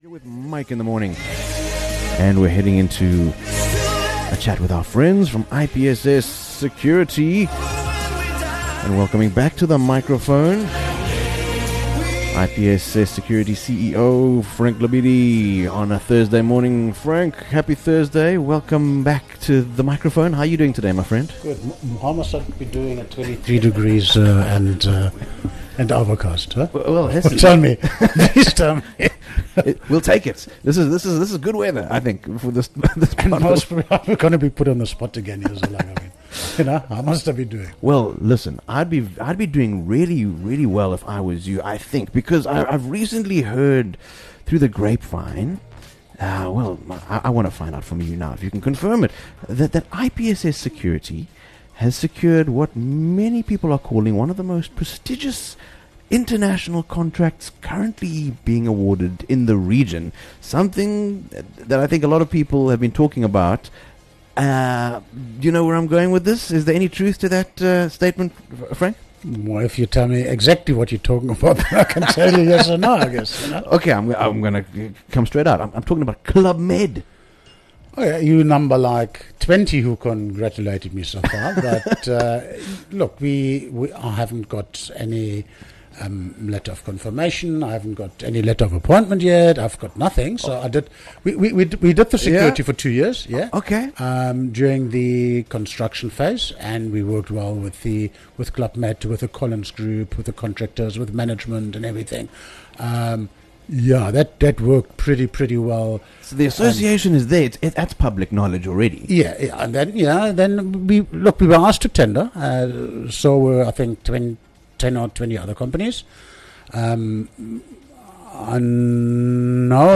The conversation highlights the strategies, innovation, and expertise required to secure some of the most prestigious developments on the North Coast.